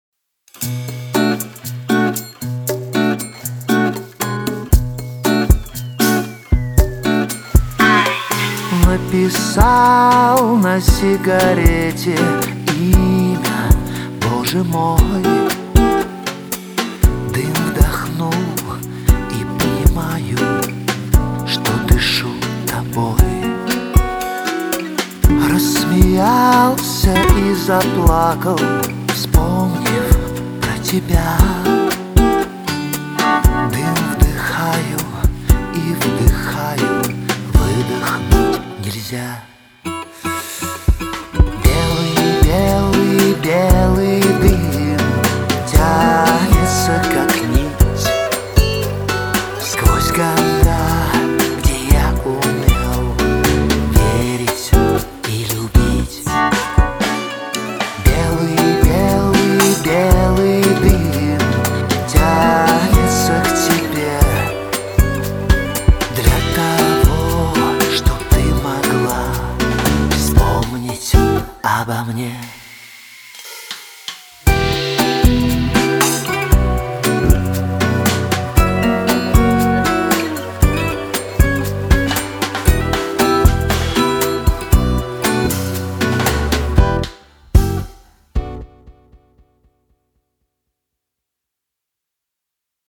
• Качество: 320, Stereo
гитара
спокойные
русский шансон
романтические